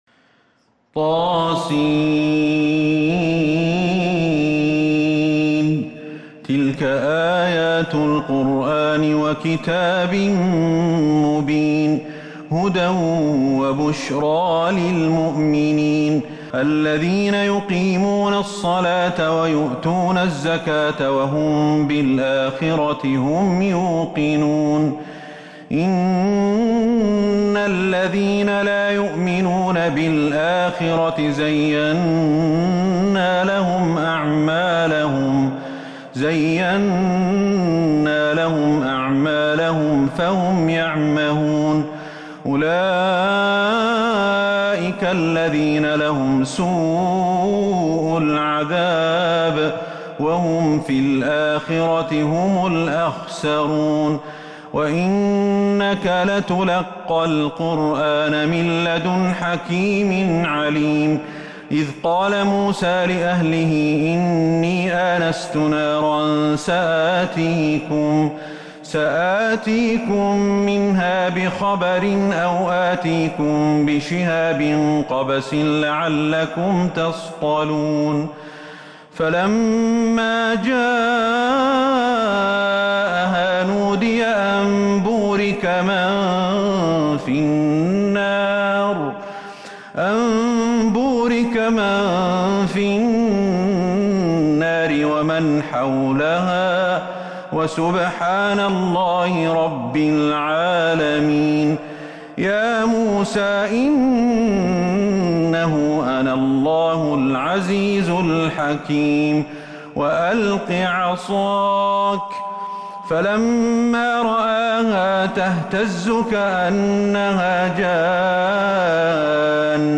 سورة النمل كاملة من تراويح الحرم النبوي 1442هـ > مصحف تراويح الحرم النبوي عام 1442هـ > المصحف - تلاوات الحرمين